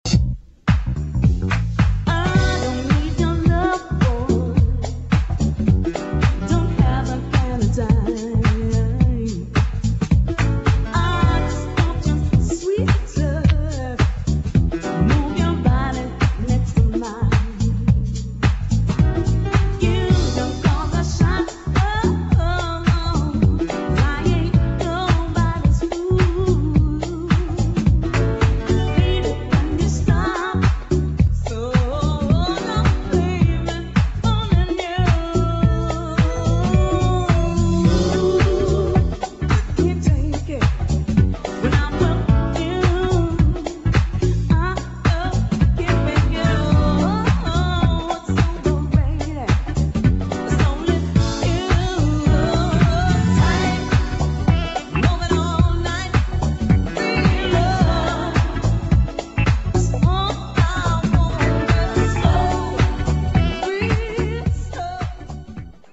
1. HOUSE | DISCO